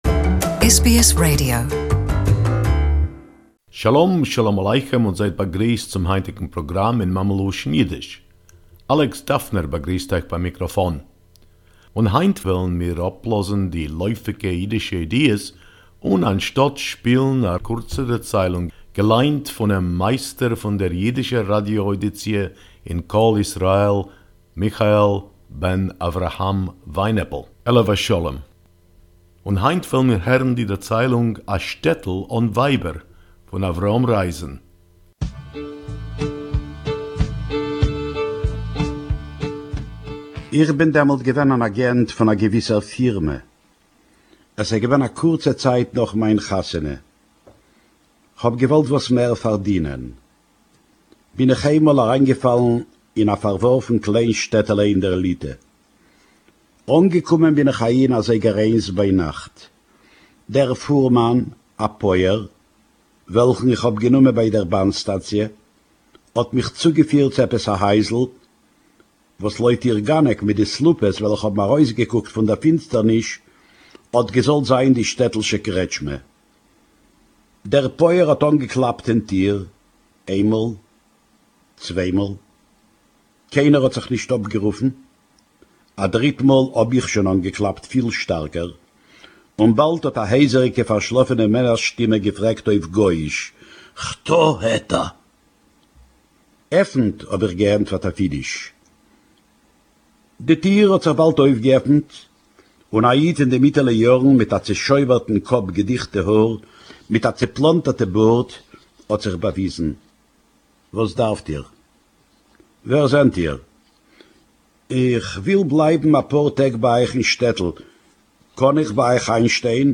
Yiddish Story: “A Village Without Wives” by Avrom Reisen